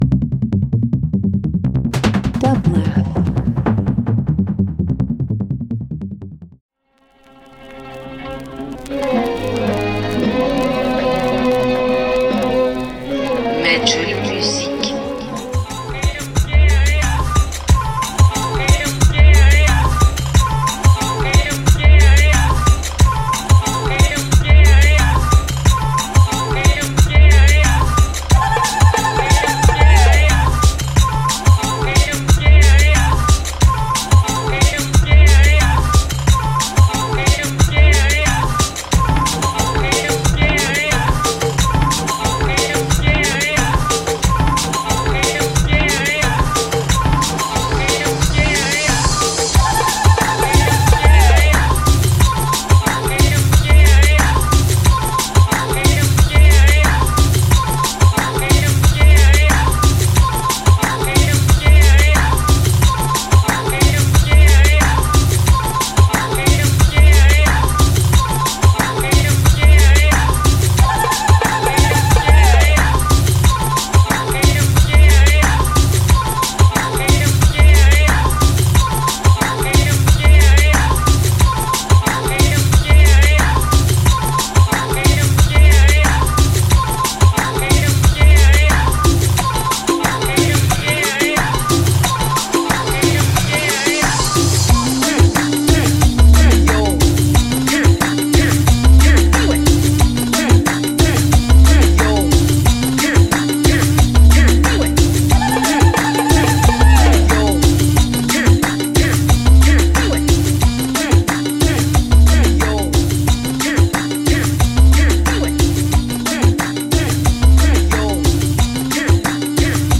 African Dance House